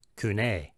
This series of posts will focus on explaining how to pronounce the Koine Greek alphabet and words using a Reconstructed Koine Pronunciation.